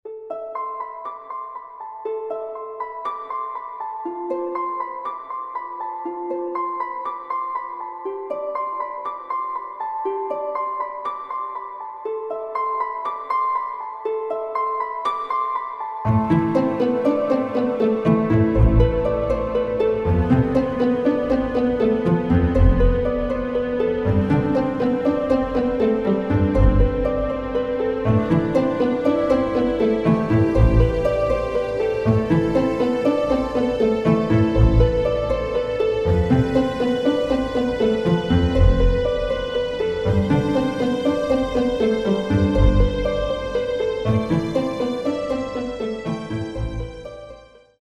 красивая мелодия
без слов , аниме , инструментальные